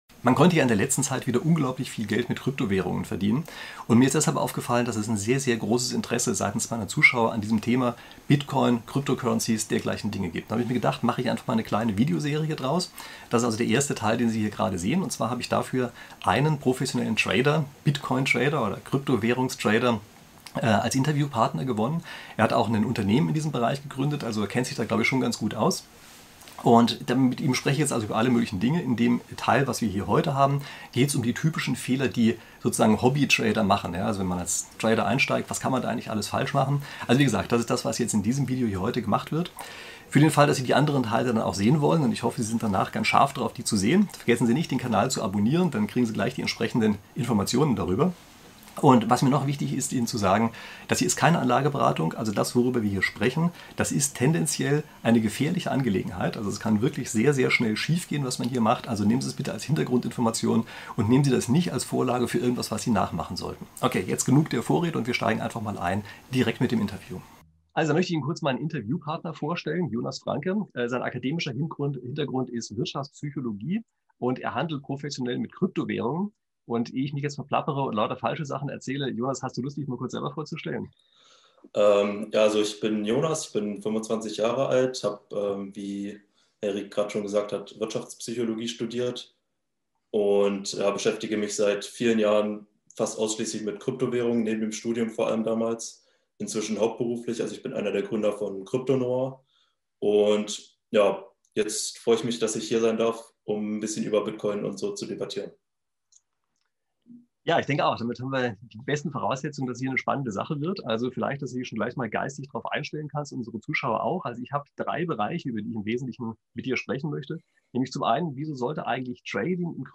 Finanz-Professor und Krypto-Trader im Gespräch: Wieso sollte man traden und welches sind die größten Anfängerfehler?